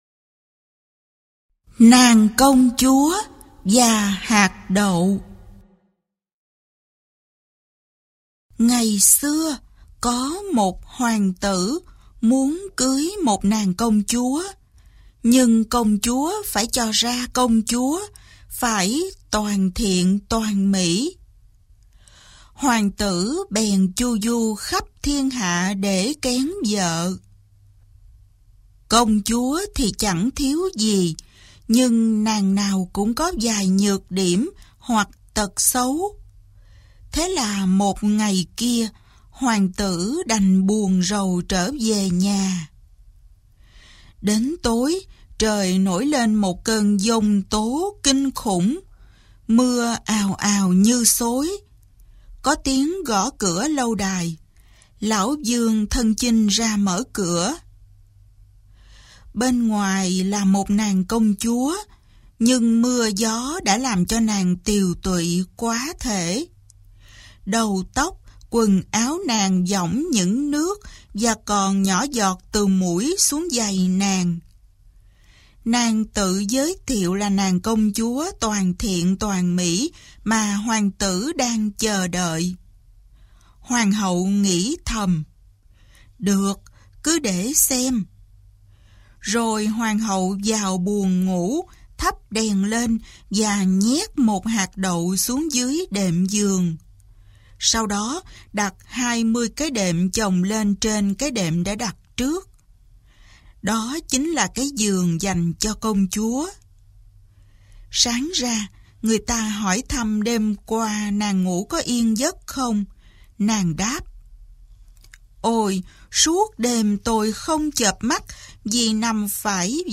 Sách nói | Truyện Cổ Andersen P25